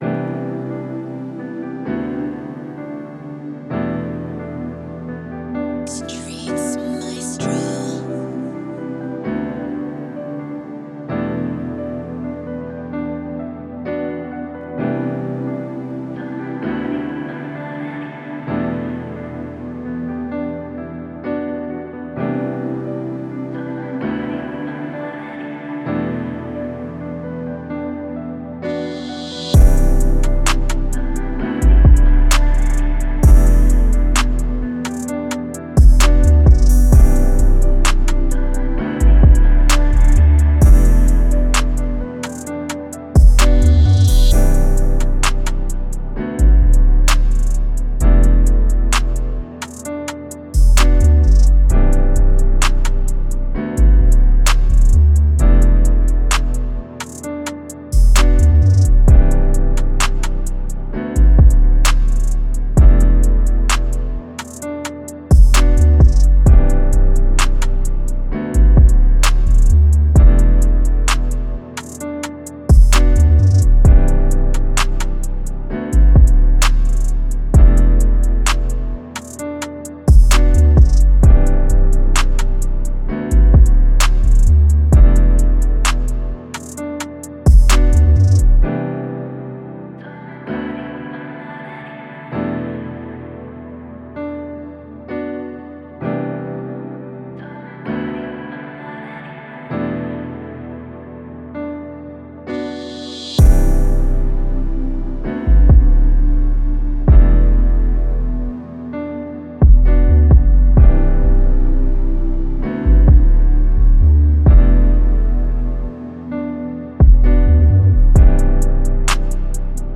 Moods: emotional, pain, intimate
Genre: Rap
Tempo: 130
BPM 130